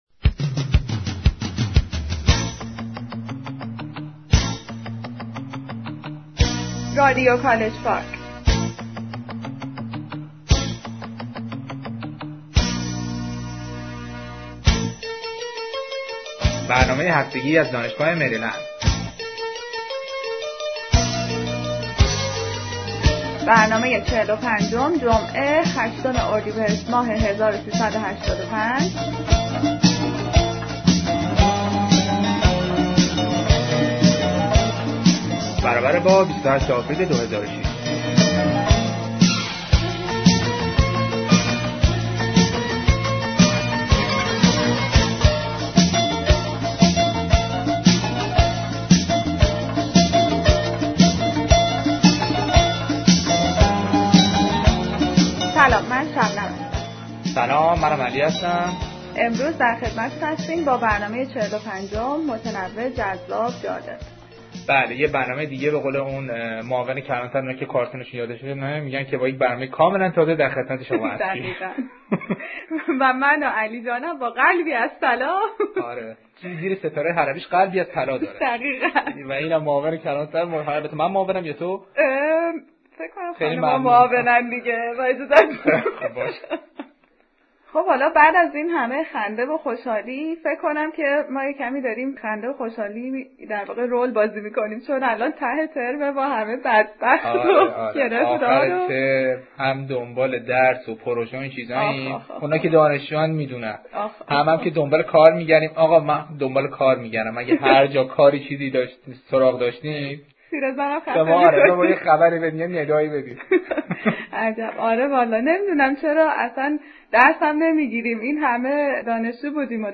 Interview
A part of the speech by satirist, Ebrahim Nabavi in University of Maryland